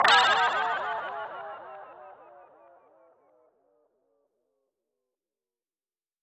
SouthSide Trap Transition (14).wav